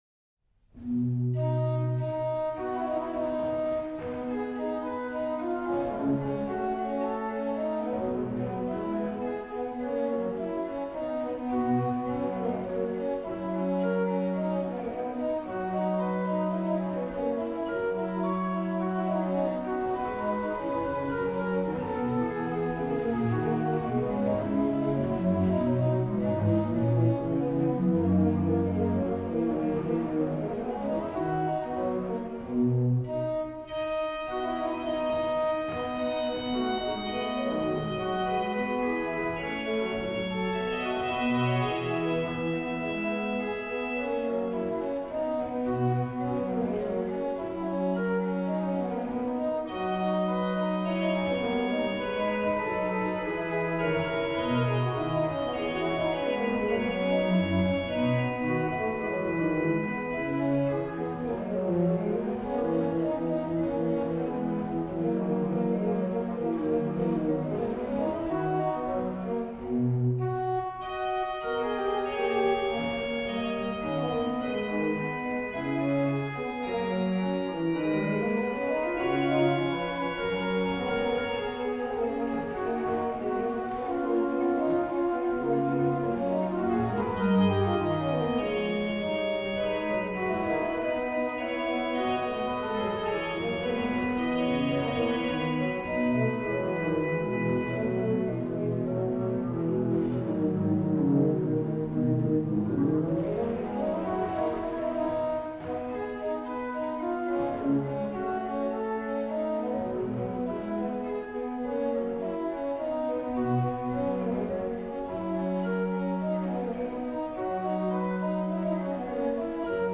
Registration   rh: BW: Ged8, Nacht4, Oct2, Sesq
lh: HW: Pr8, Viol8
Ped: Oct8